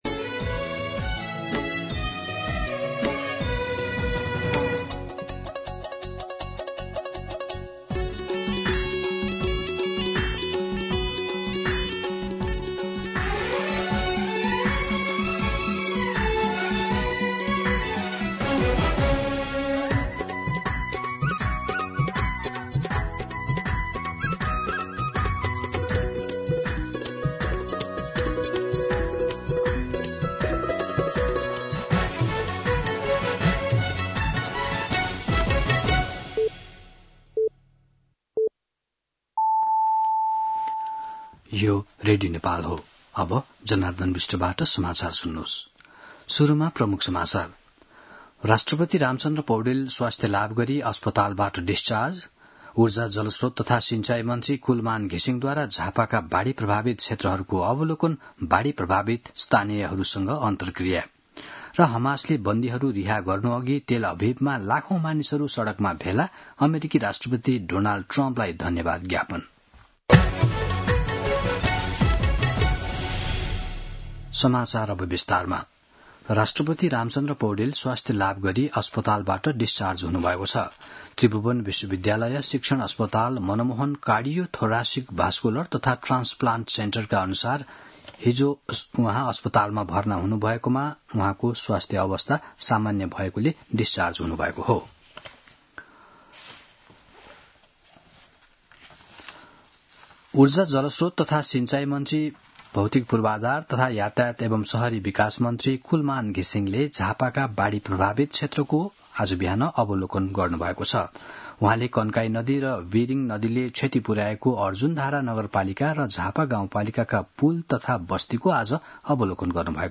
दिउँसो ३ बजेको नेपाली समाचार : २६ असोज , २०८२
3-pm-Nepali-News-5.mp3